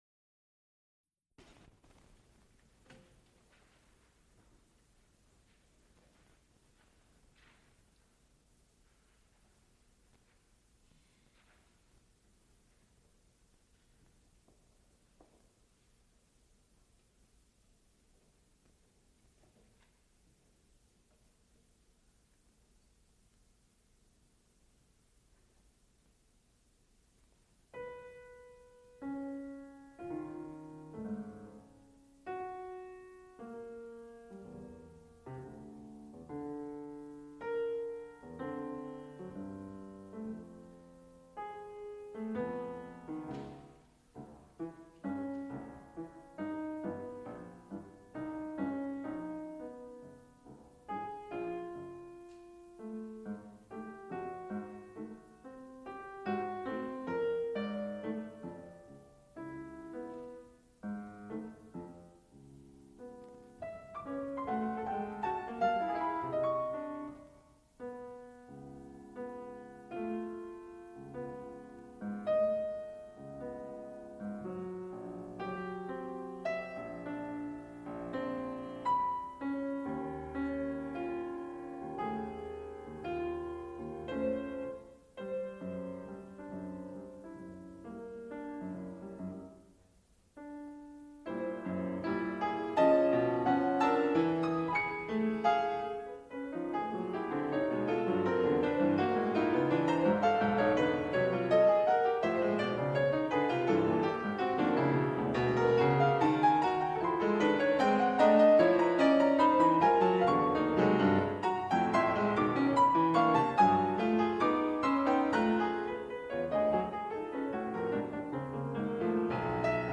Recital of twentieth-century music | Digital Pitt
Extent 4 audiotape reels : analog, quarter track, 7 1/2 ips ; 7 in.
musical performances
Piano music
Flute and harpsichord music